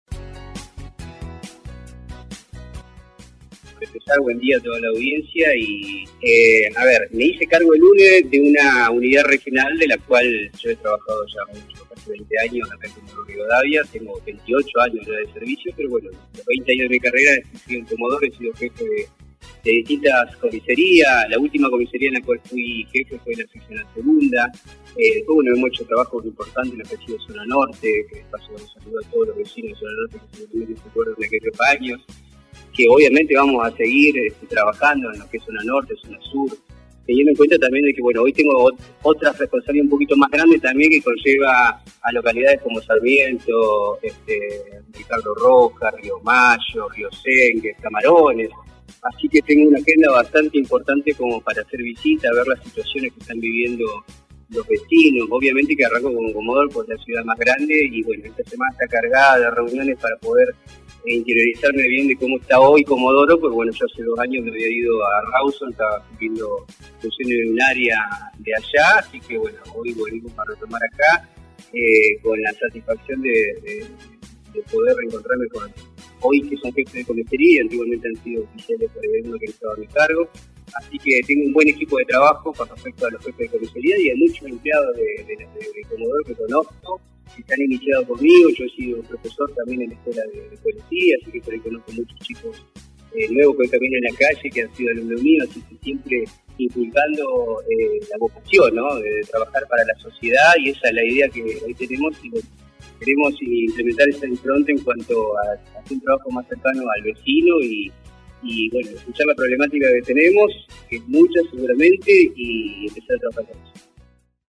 El comisario mayor Lucas Cocha es el nuevo Jefe de la Unidad Regional de Comodoro Rivadavia y pasó por el aire de LA MAÑANA DE HOY: